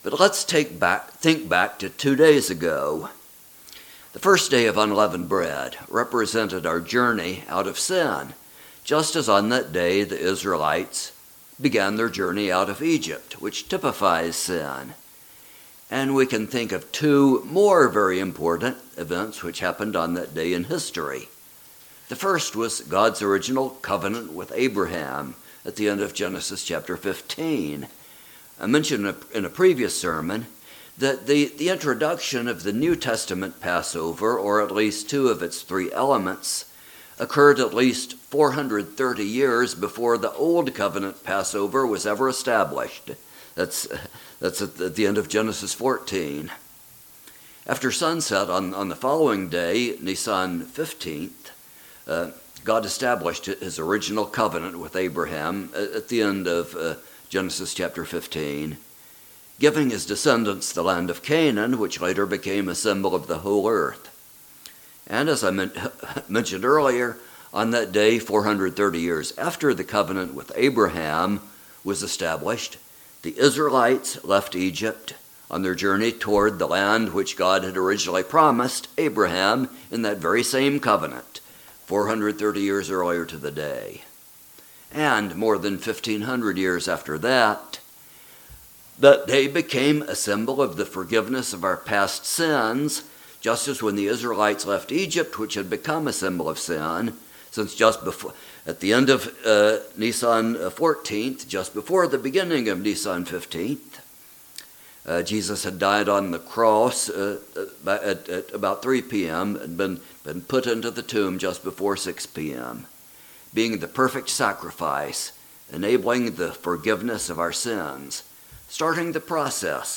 Given in Roanoke and Kingsport on First Day of Unleavened Bread, April 6, 2023